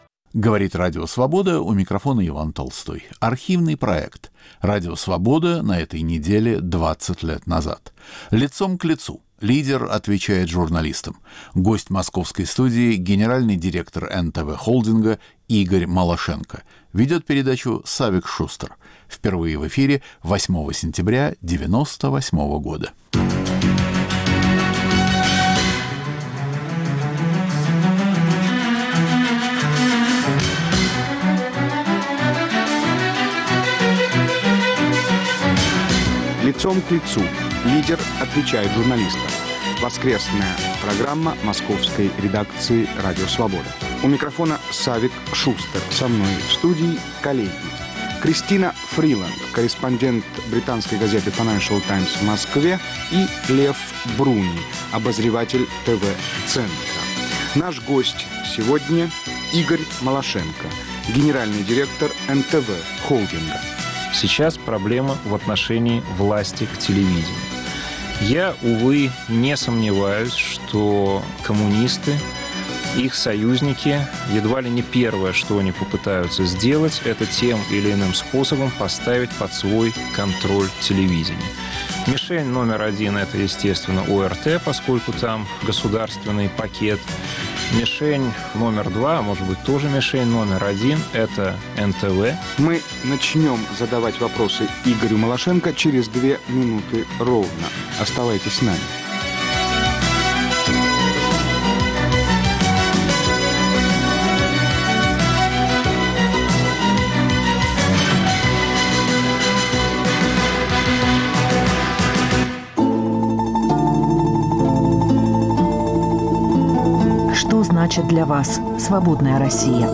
Радио Свобода на этой неделе 20 лет назад: Беседа с гендиректором НТВ Игорем Малашенко
Архивный проект. Иван Толстой выбирает из нашего эфира по-прежнему актуальное и оказавшееся вечным.